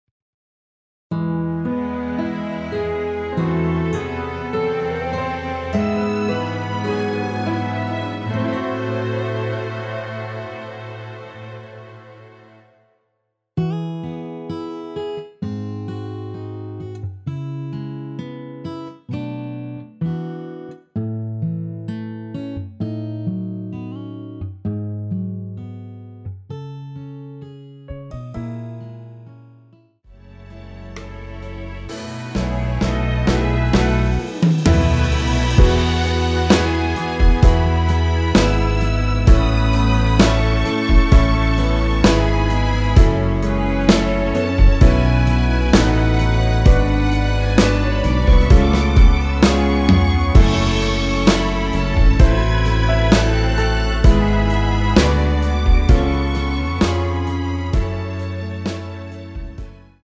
원키에서(+2)올린 MR입니다.
앞부분30초, 뒷부분30초씩 편집해서 올려 드리고 있습니다.
중간에 음이 끈어지고 다시 나오는 이유는